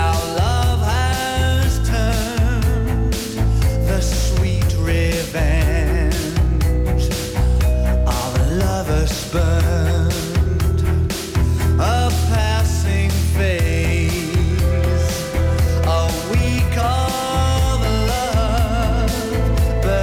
Тюнер продемонстрировал хорошее качество звука в FM-режиме (